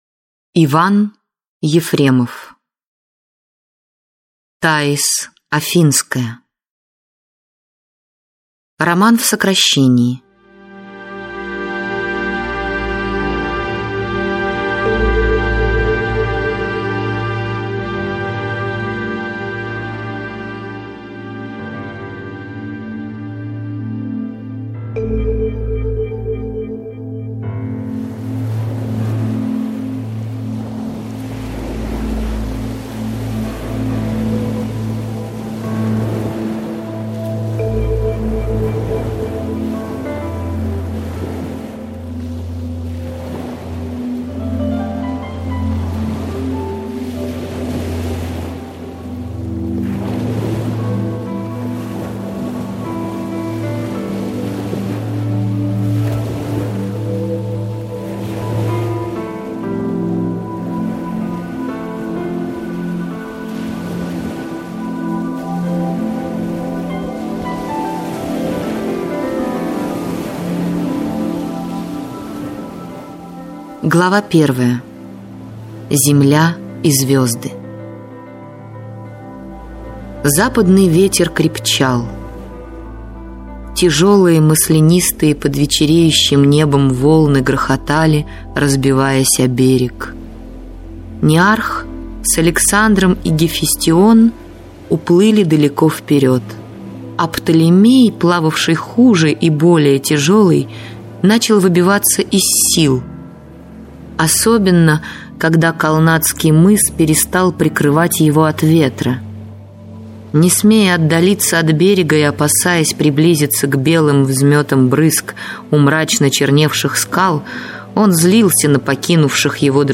Аудиокнига Таис Афинская (сокращенная аудиоверсия) | Библиотека аудиокниг